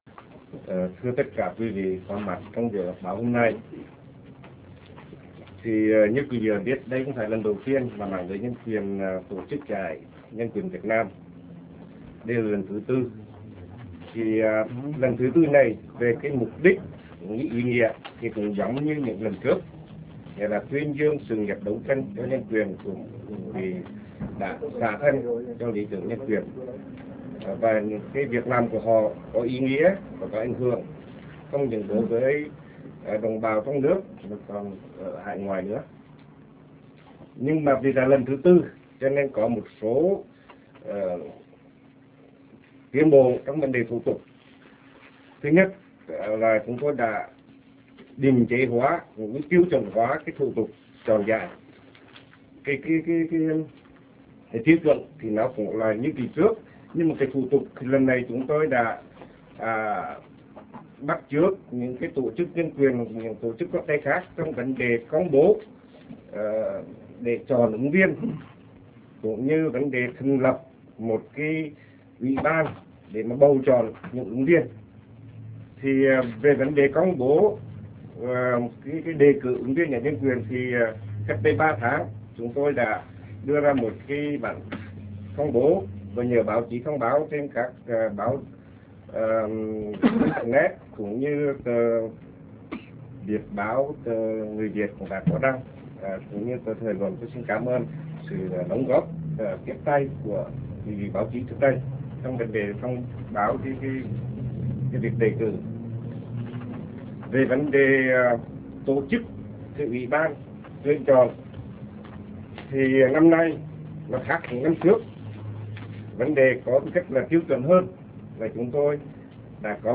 MẠNG LƯỚI NH�N QUYỀN HỌP B�O